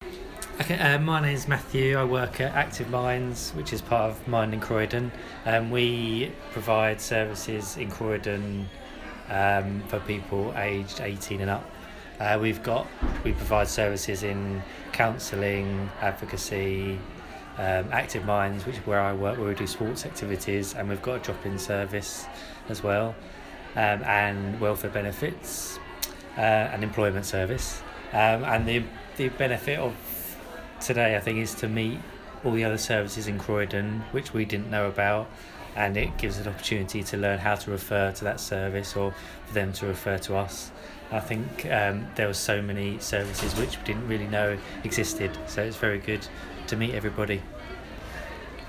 on our Mental Health Forum market place on 8/3/2017 and what his project provides in the borough.